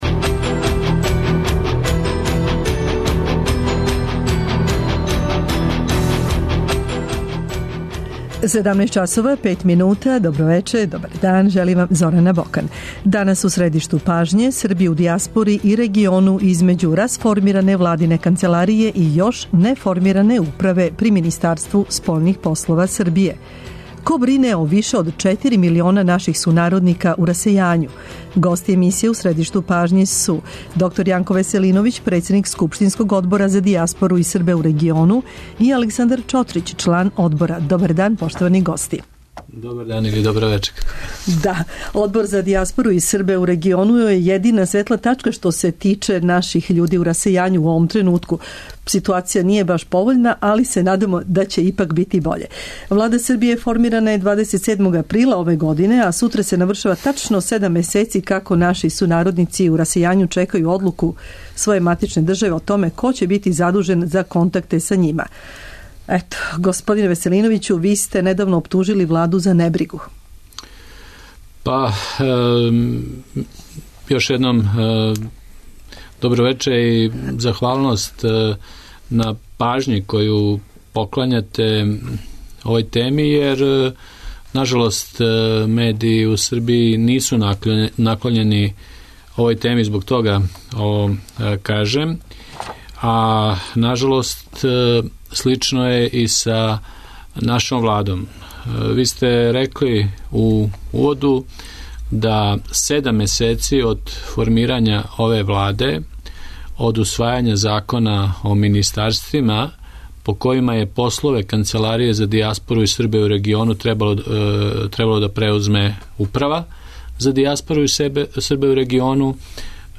На питања - ко у име државе Србије брине о више од 4 милиона наших сународника у расејању и са којим проблемима се наша дијаспора суочава одговараће гости емисије: др Јанко Веселиновић председник Скупштинског Одбора за дијаспору и Србе у региону и Александар Чотрић члан Одбора.
Из Будимпеште се укључује Љубомир Алексов дугогодишњи председник Самоуправе Срба у Мађарској, а од ове године заступник српске заједнице у мађарском Парламенту.